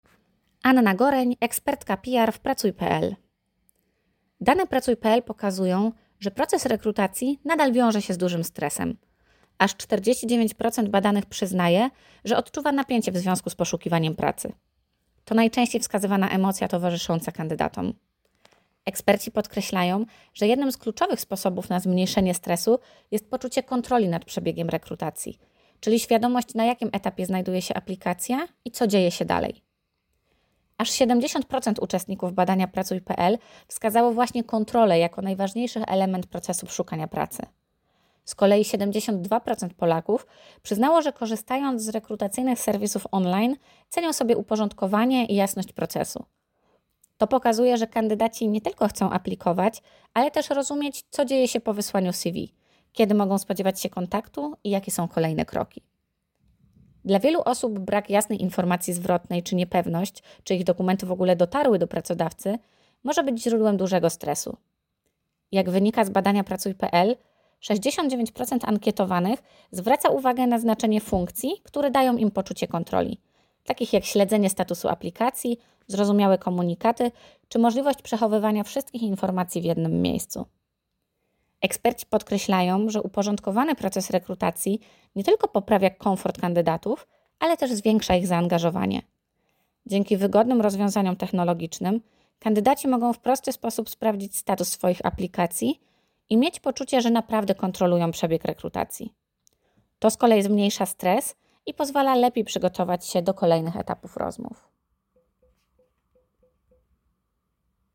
Skorzystaj z komentarza AUDIO